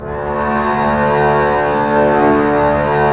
Index of /90_sSampleCDs/Roland LCDP13 String Sections/STR_Cbs FX/STR_Cbs Sul Pont